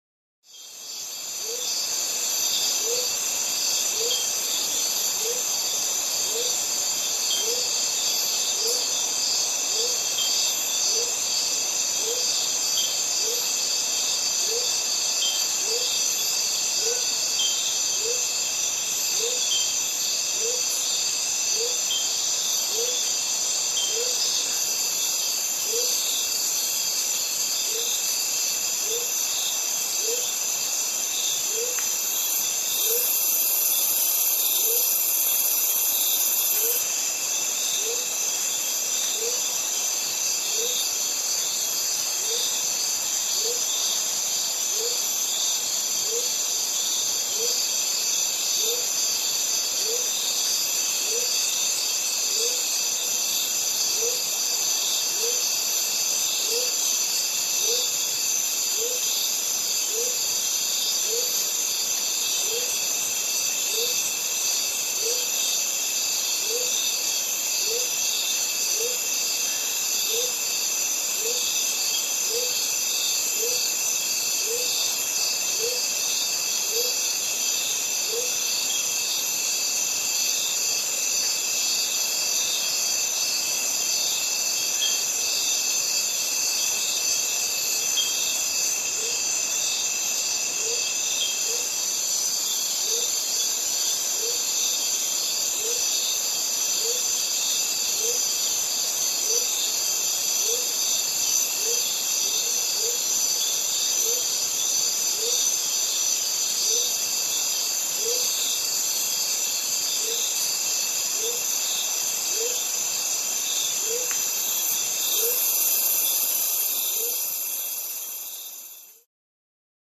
Леса звуки скачать, слушать онлайн ✔в хорошем качестве